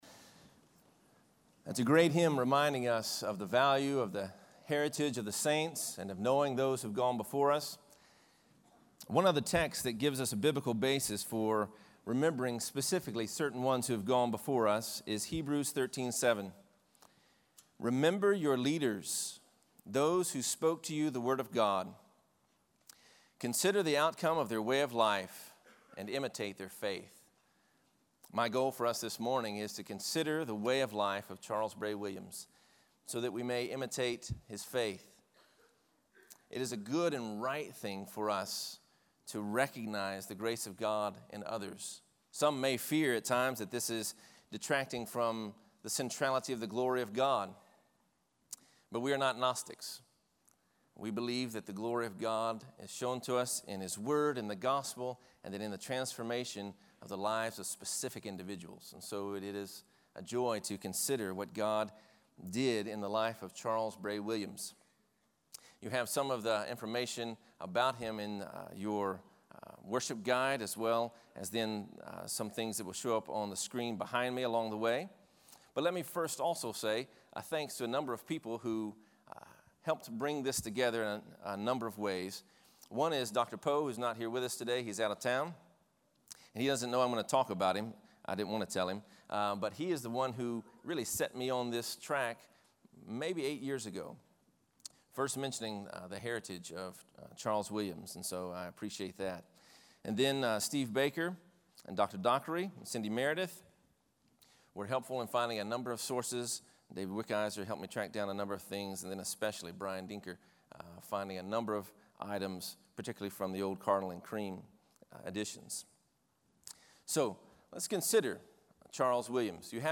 Founders Day Chapel: